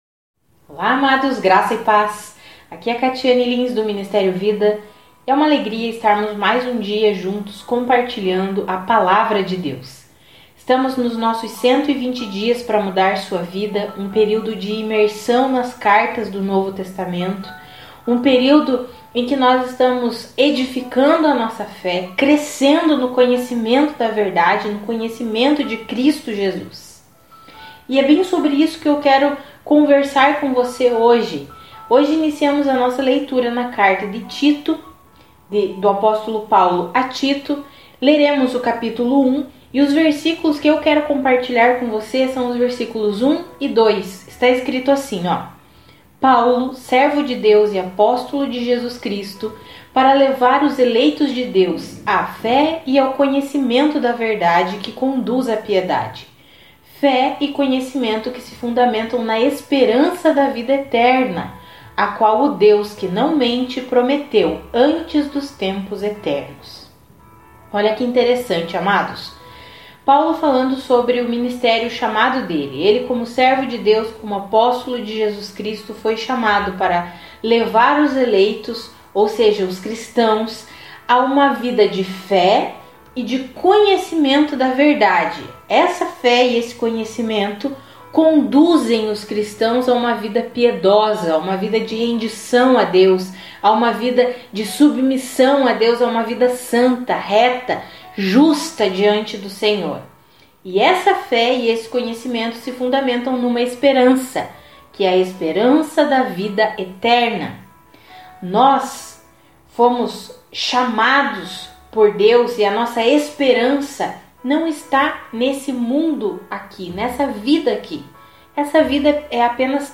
Além da leitura Bíblica nós fornecemos um devocional escrito e um devocional em áudio, todos os dias, para edificar a sua fé.